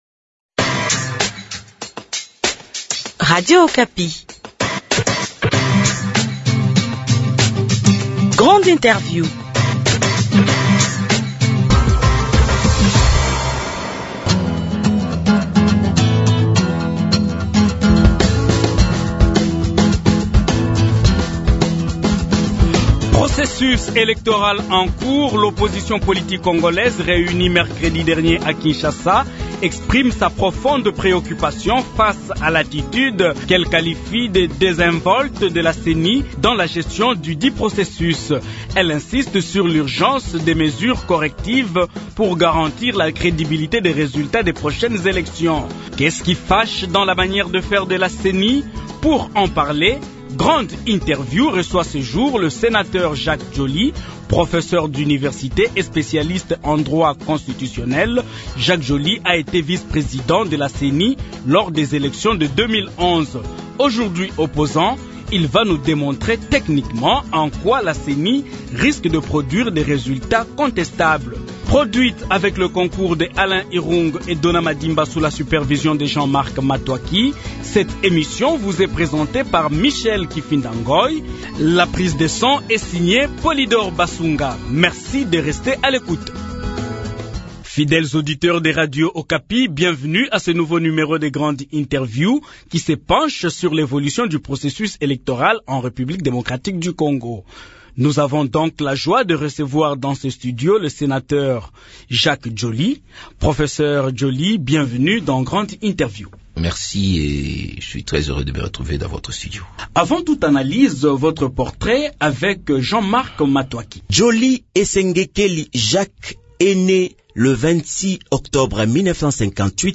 Processus électoral en cours, le sénateur Jacques Djoli réaffirme la réprobation de l’Opposition politique sur la machine à voter et sur le fichier électoral publié par la Ceni. Ancien vice-président de la Ceni lors des élections de 2011, le sénateur Jacques Djoli est l’invité de Grande Interview ce samedi 14 avril 2018.